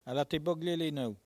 Patois - archive